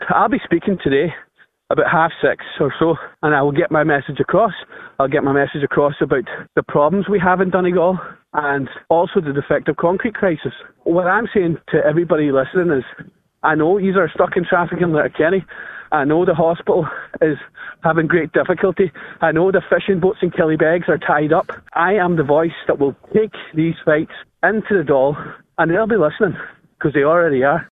On today’s Nine til Noon Show, he confirmed he will be speaking during the debate on the new cabinet this evening, and while he will highlight the defective concrete crisis, he will also raise other local issues…………..